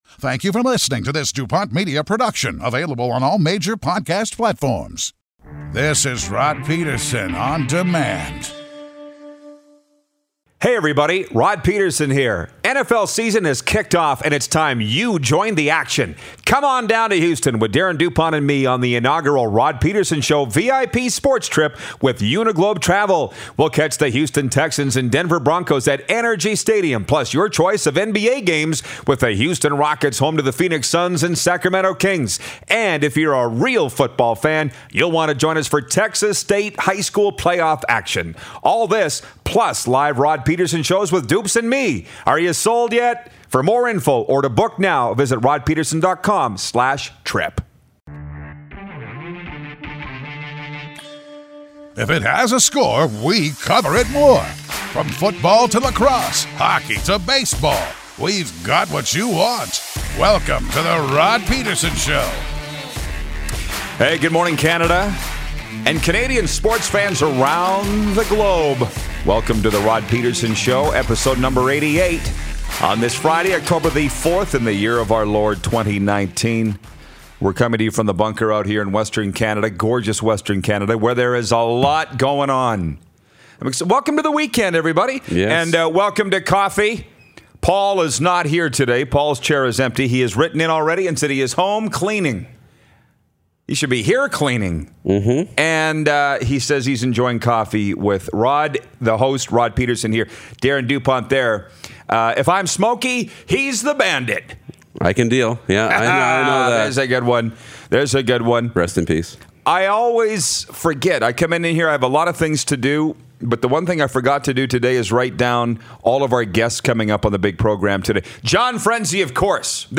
When we hit 88 episodes… you’re gonna see some serious sports talk!